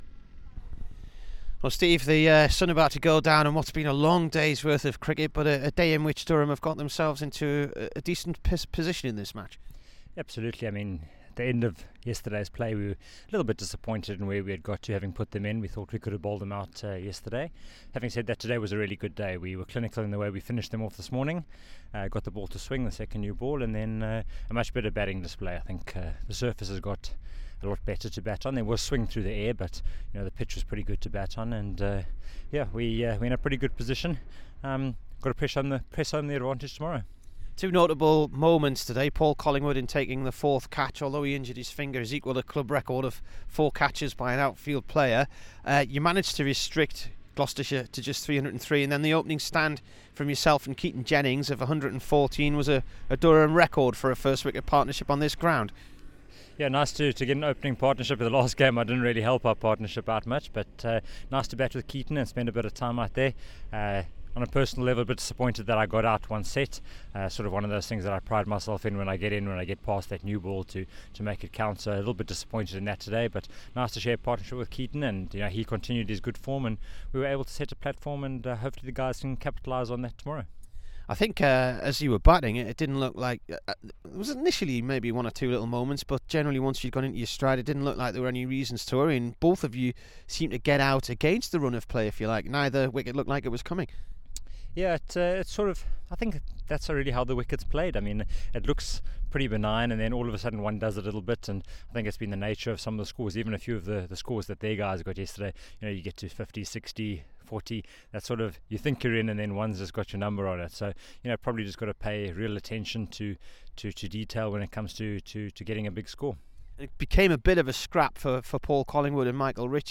STEVE COOK INT
Here is the Durham and South Africa opener Steve Cook after his 64 on day 2 v Gloucestershire.